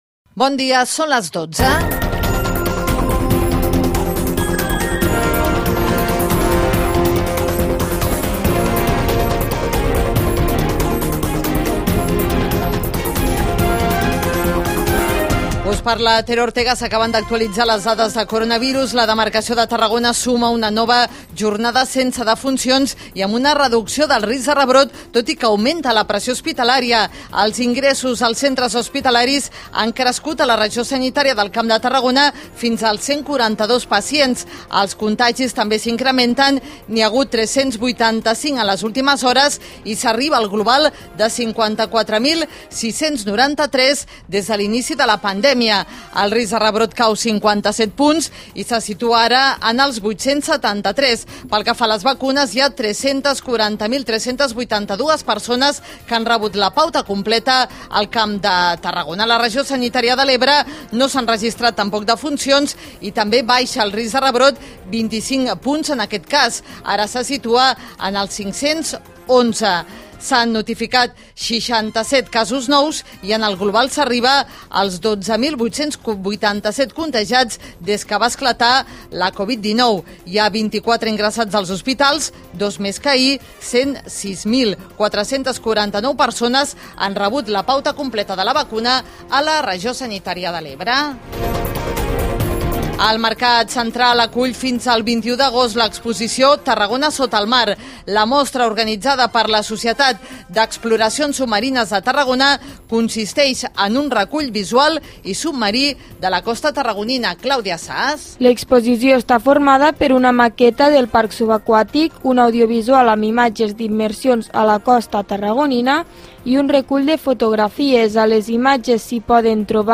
Butlletí informatiu: La pandèmia de COVID - Tarragona Ràdio, 2021